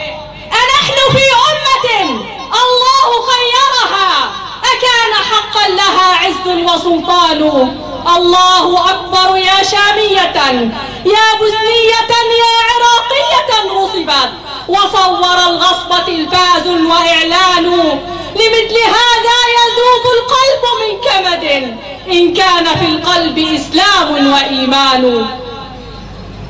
أبيات شعرية مقتطعة - أنحن في أمة الله خيرها من كلمة النساء من مؤتمر الخلافة (حافظة للعرض) بغزة في الذكرى الـ93 لهدم الخلافة